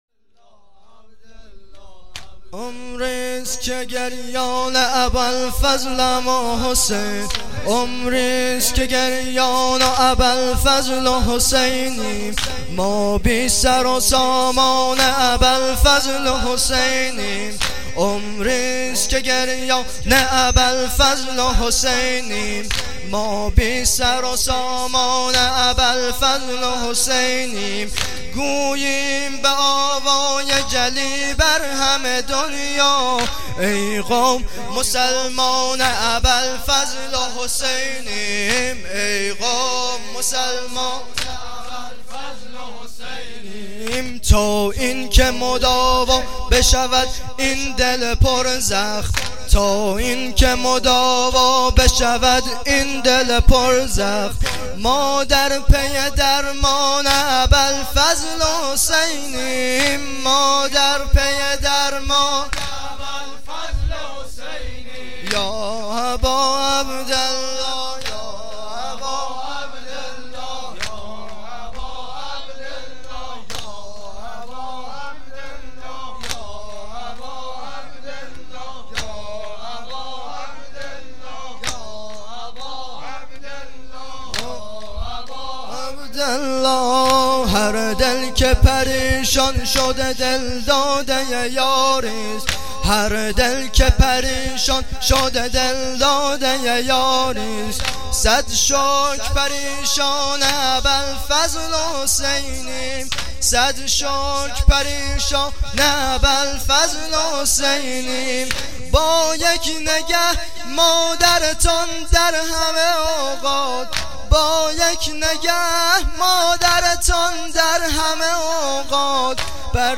🏴مراسم هفتگی 🔸پنجشنبه۲۱تیر ۹۷